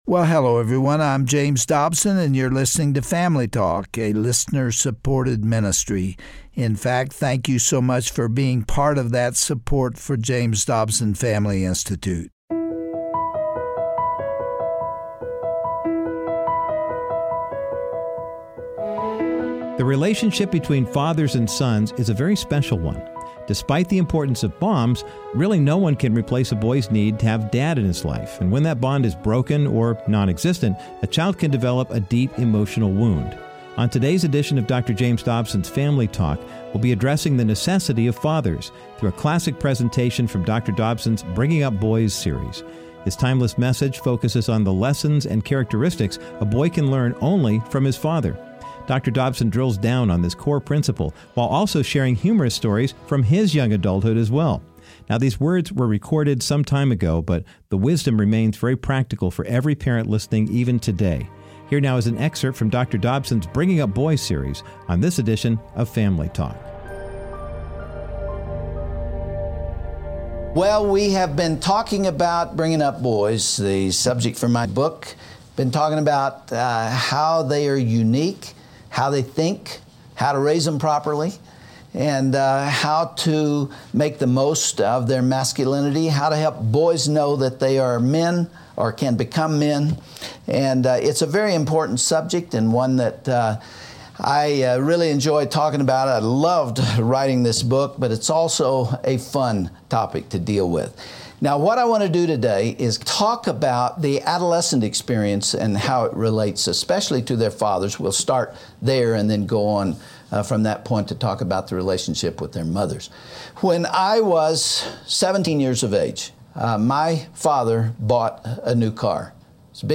On today’s edition of Family Talk, Dr. James Dobson shares a timeless message from his “Bringing Up Boys” teaching series, in which he emphasizes the absolute need that every boy has for a father or a strong male role model. While there is nothing quite like a mother’s love, Dr. Dobson explains the unique bond that fathers and sons have, and why that relationship is so critical.